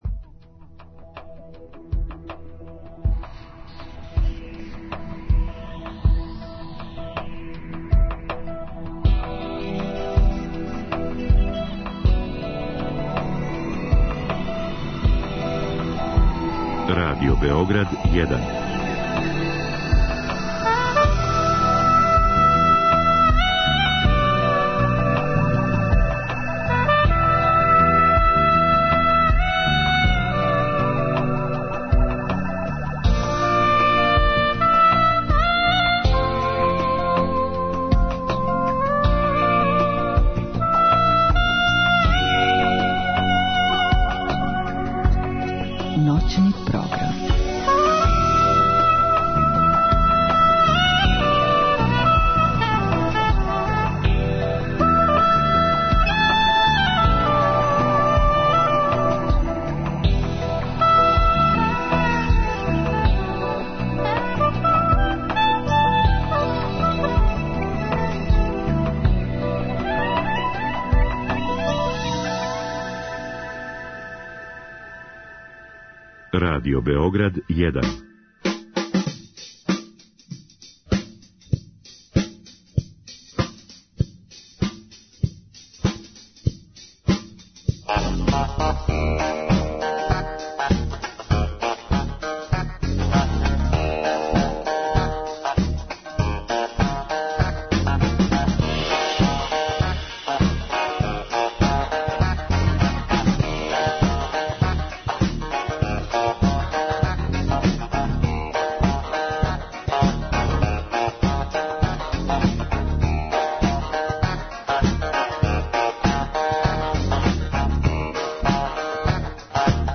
Они ће и бити са нама у студију.